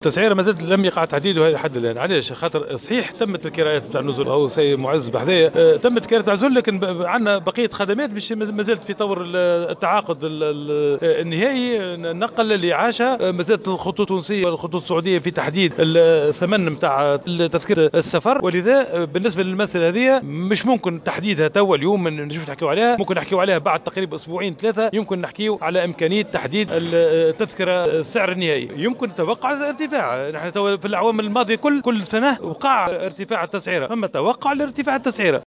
قال وزير الشؤون الدينية أحمد عظوم إن تسعيرة الحج لهذا الموسم لم تحدد بعد، لأن بعض الخدمات مازالت في طور التعاقد على غرار النقل والاعاشة وتذكرة السفر.